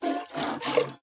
minecraft / sounds / entity / cow / milk3.ogg
milk3.ogg